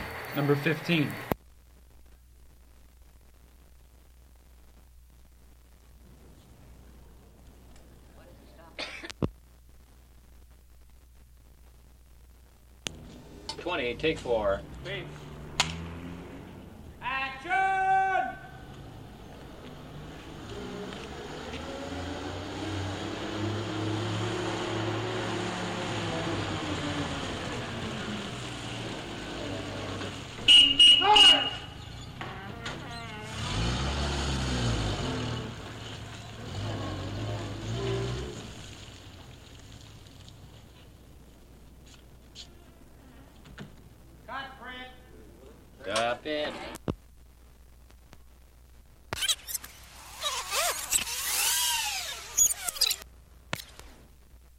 老式卡车 " G1415A型卡车
描述：在，喇叭发出哔哔声。导演大喊“行动！”开始时。 这些是20世纪30年代和20世纪30年代原始硝酸盐光学好莱坞声音效果的高质量副本。 40年代，在20世纪70年代早期转移到全轨磁带。我已将它们数字化以便保存，但它们尚未恢复并且有一些噪音。
Tag: 卡车 交通运输 光学 经典